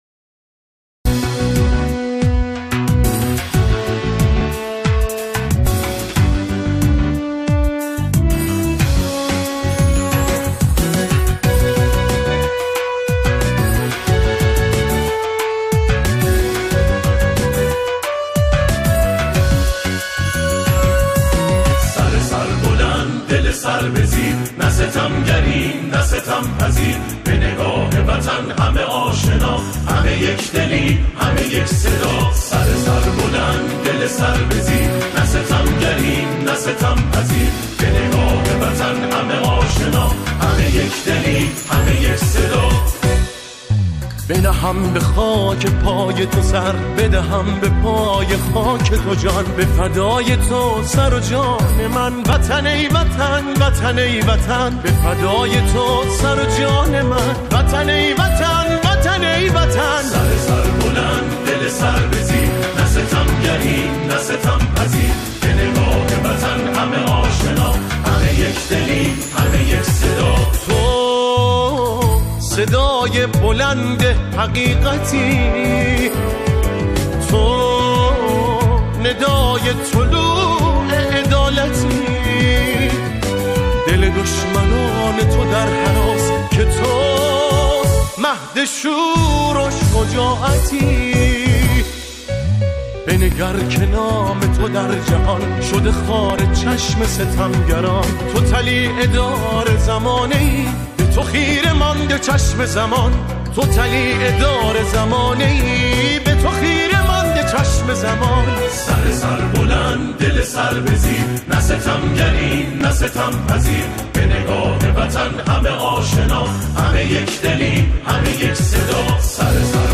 همخوانی
گروه کر